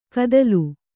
♪ kadalu